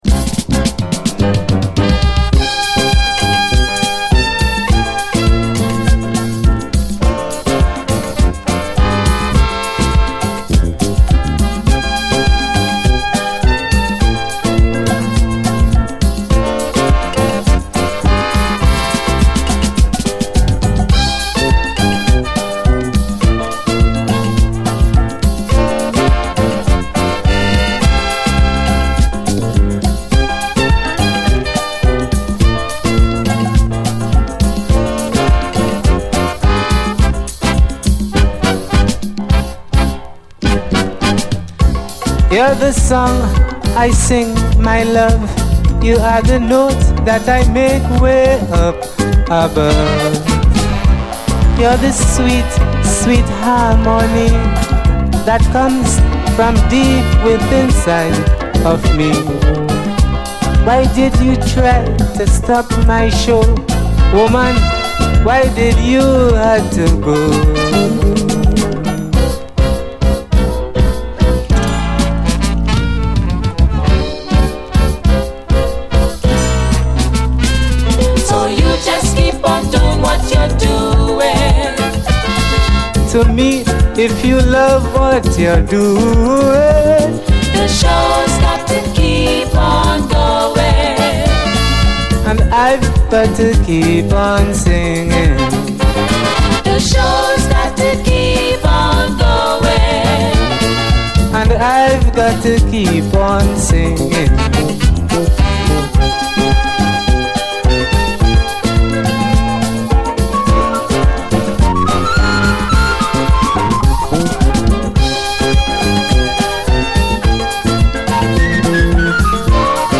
CARIBBEAN
トリニダード産のレア・メロウ・カリビアン・ディスコ！
後半にはスティール・パンも交えてさらにトロピカルに盛り上げます！